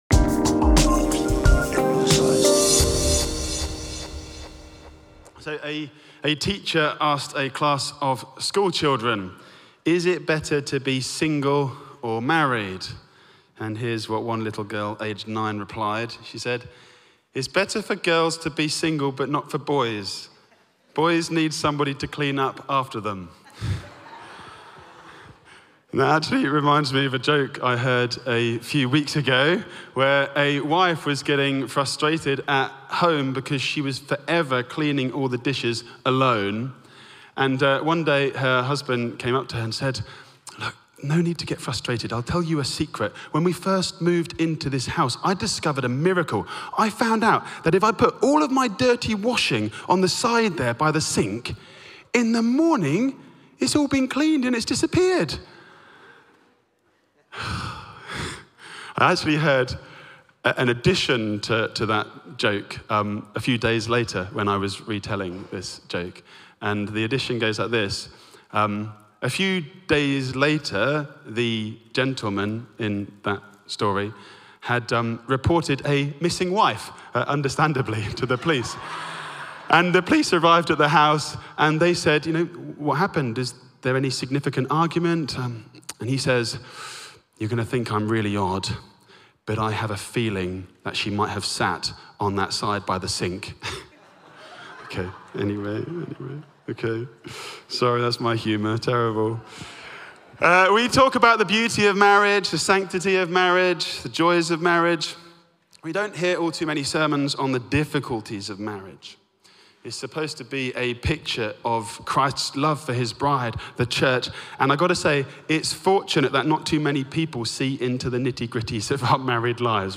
Audio Sermon
The-Morning-Sermon-16.3.25.mp3